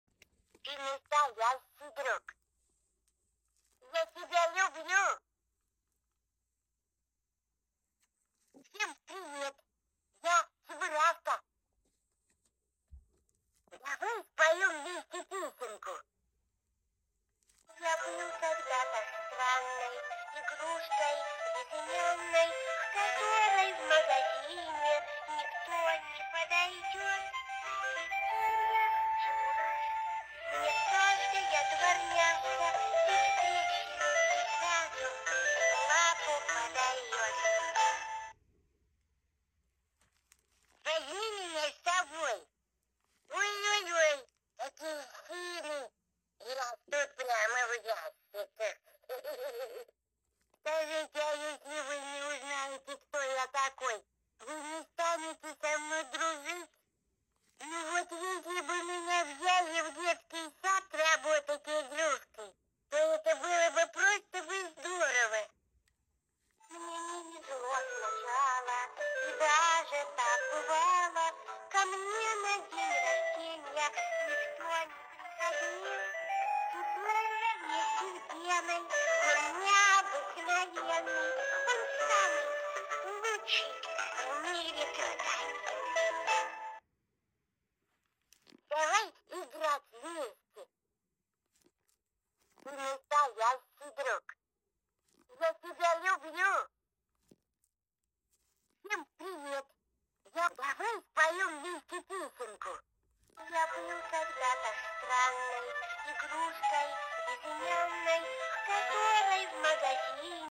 Cheburashka Чебурашка Russian speaking plush sound effects free download